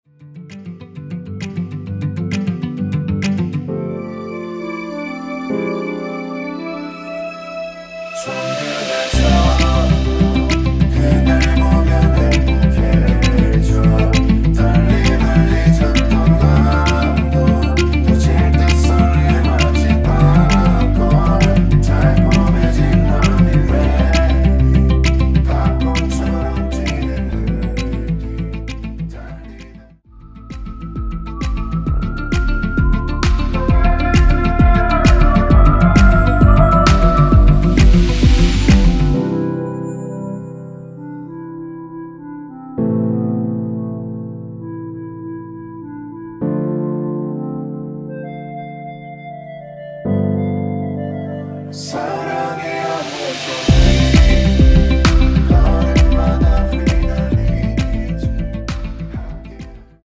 원키에서(-2)내린 멜로디와 코러스 포함된 MR입니다.
앞부분30초, 뒷부분30초씩 편집해서 올려 드리고 있습니다.
중간에 음이 끈어지고 다시 나오는 이유는